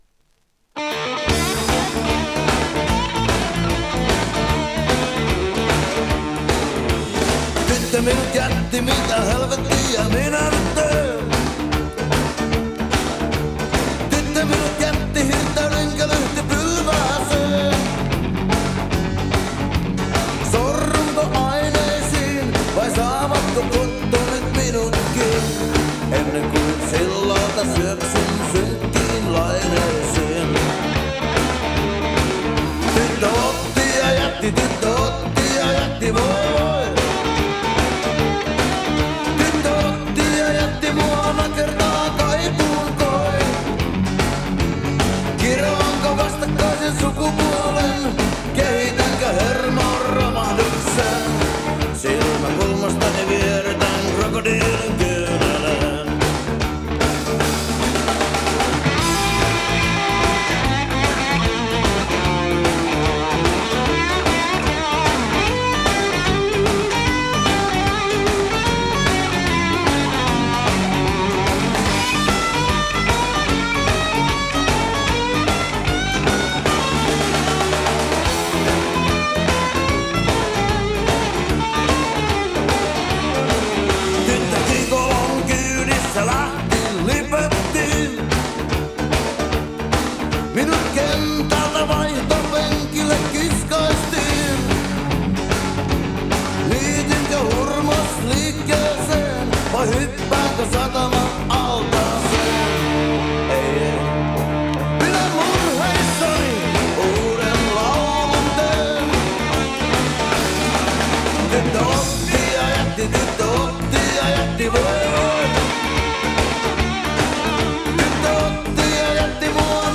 Studio oli Takomo, Helsingissä.